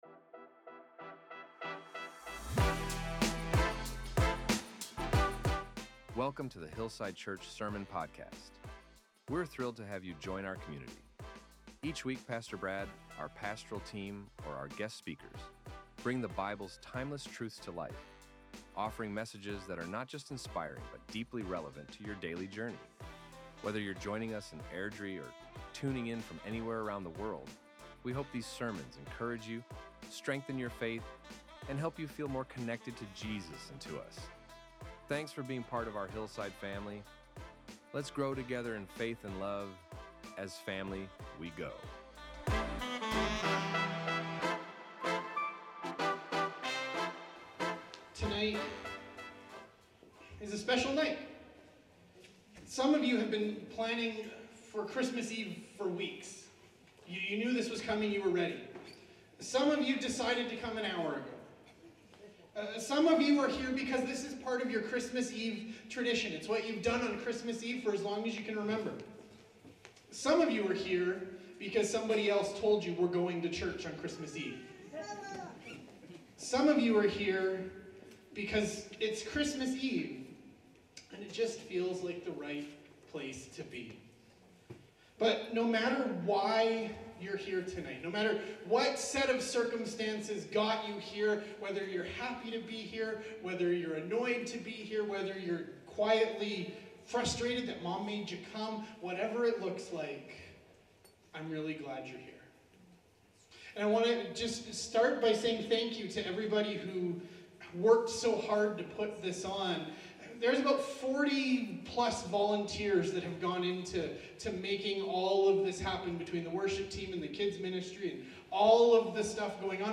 Our Sermons | HILLSIDE CHURCH
On Christmas Eve, we gathered to celebrate the heart of the Christmas story—that God keeps His promises, even when they arrive in unexpected ways.